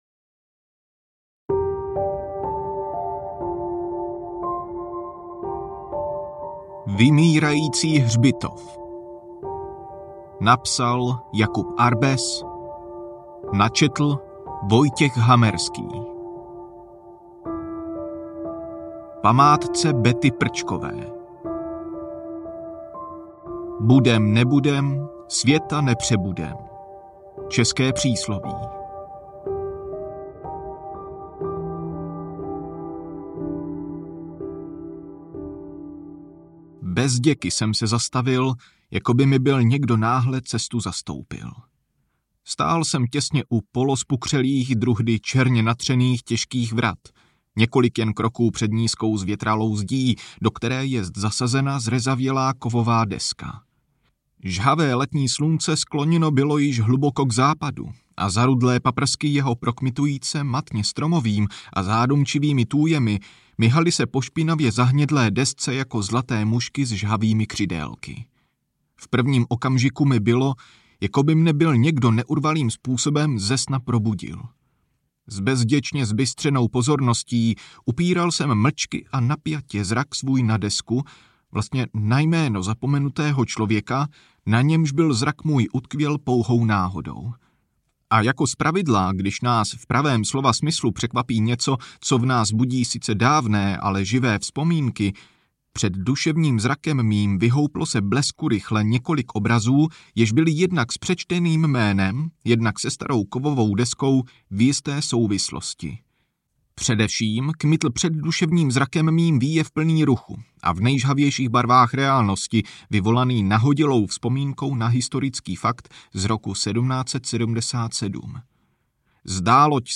Vymírající hřbitov audiokniha
Ukázka z knihy